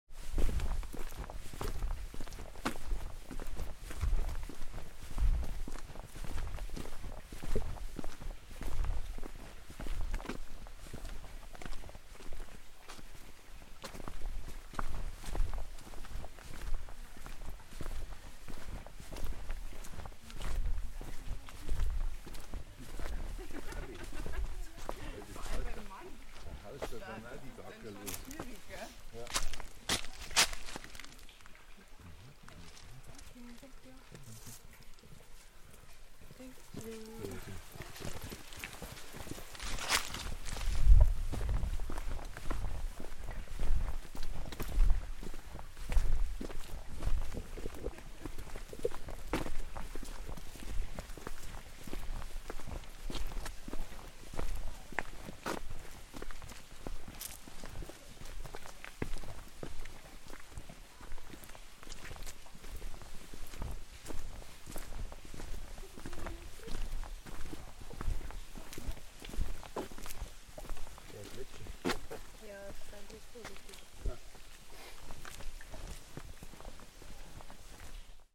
This recording captures my experience walking along a "levada," a traditional water channel originally built to transport water from the north of the island.
In the recording, you can hear a unique aspect of these trails: the interactions with fellow walkers.
These brief stops create moments of spontaneous interaction—exchanges of greetings, expressions of gratitude, and sometimes even light conversation.
The soundscape is dominated by the melodies of birds, wind, and the soothing presence of water—whether gently flowing through the channels or rushing in small streams. Together, these sounds create a gentle, immersive drone that enhances the peacefulness of the environment.